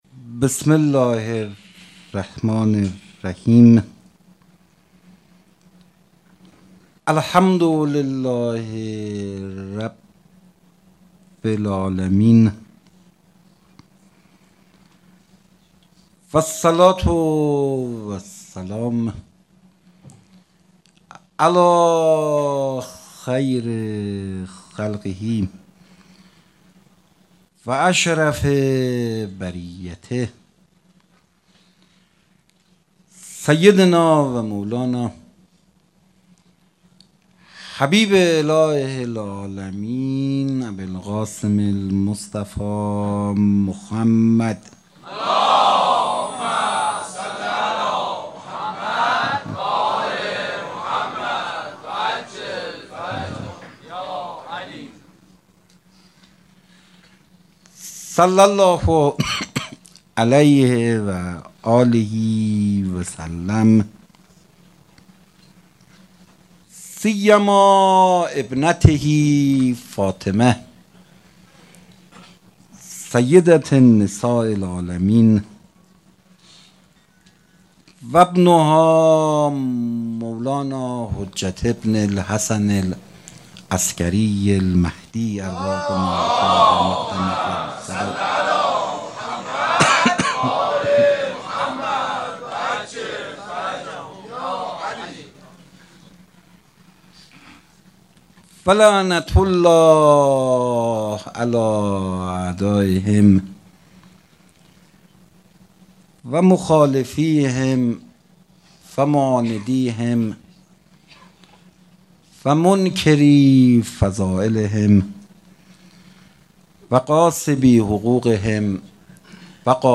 سخنرانی
با اینکه پیکر پسرش بوریا شود روضه محمود کریمی